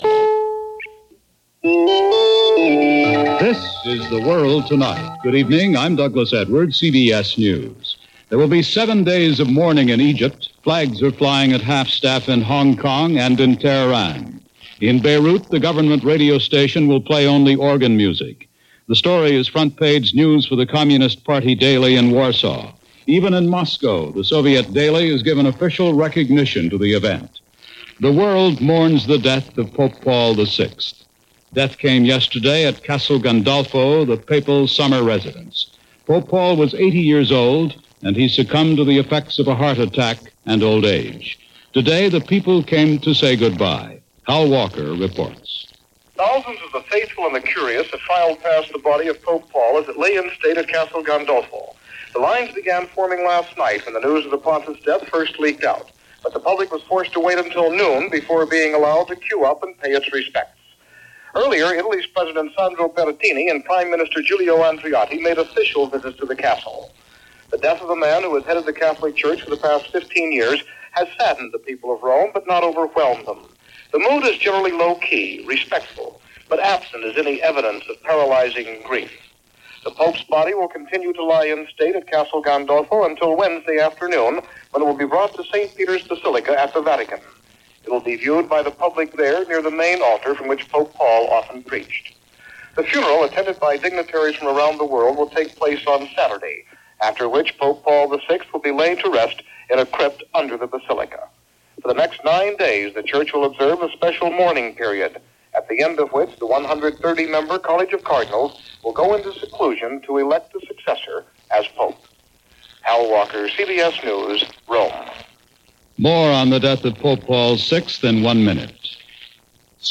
August 7, 1978 – CBS Radio – The World Tonight – Gordon Skene Sound Collection
And that’s just a small slice of what happened, this August 7, 1978 as reported by CBS Radio’s The World Tonight.